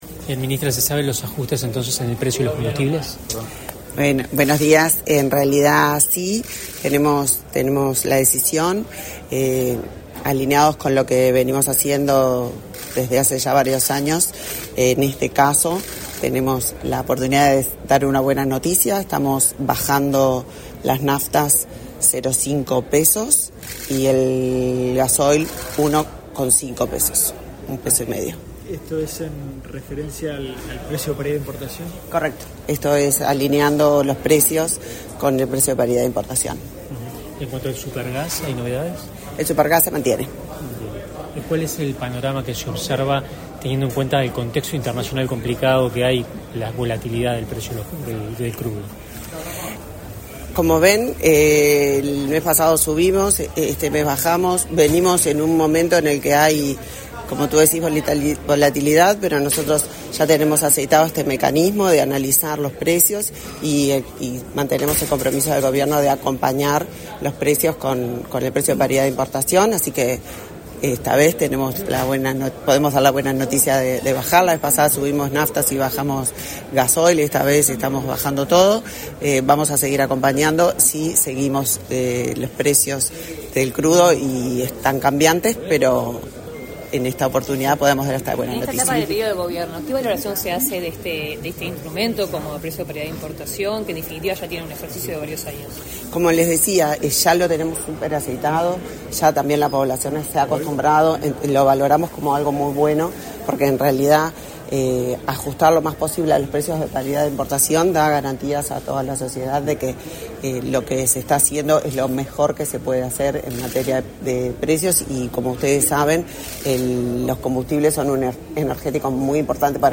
Declaraciones a la prensa de la ministra de Industria, Energía y Minería, Elisa Facio